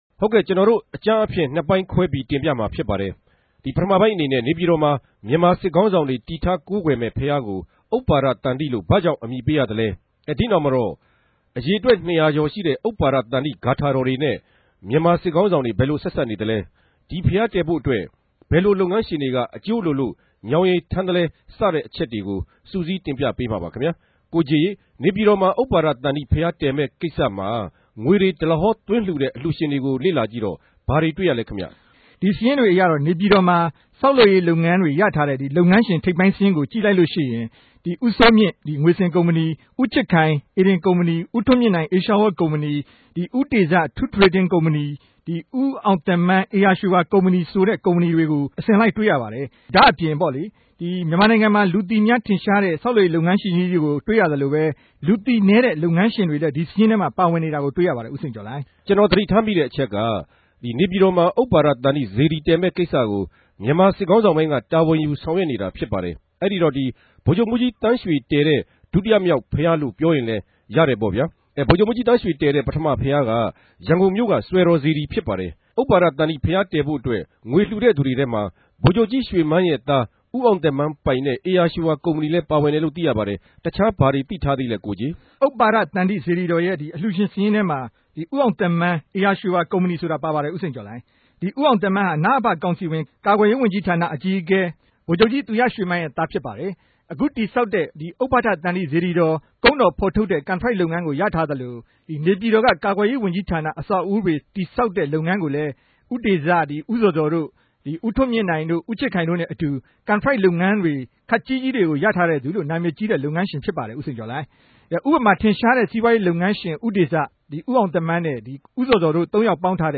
ဗန်ကောက်္ဘမိြႚမြာရြိတဲ့ RFA႟ုံးခြဲကနေ ပူးတြဲ သတင်းပေးပိုႛမြာ ူဖစ်ပၝတယ်။